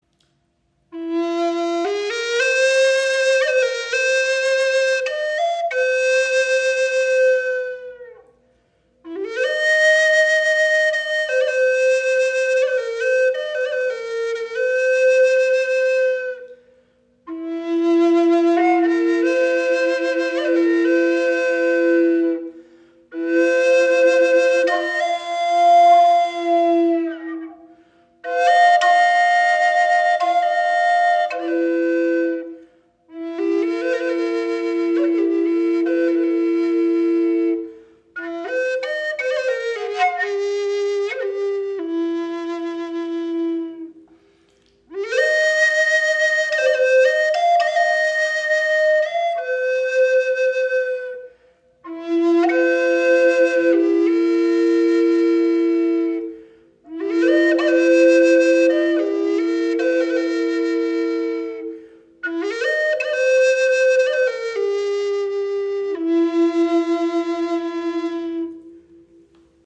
Klangbeispiel
Diese wundervolle Doppelflöte ist auf F Moll in 432 Hz gestimmt und erzeugt eine magisch wirkende, warme Klangfarbe.